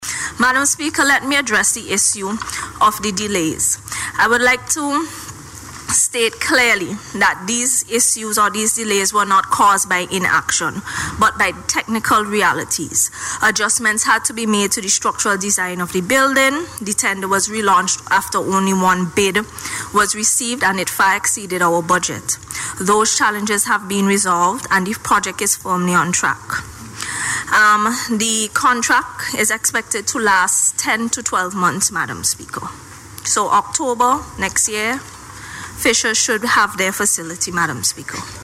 Minister of Urban Development, Energy, and Seaports, Benarva Browne, made the announcement in Parliament while responding to questions on the project’s progress.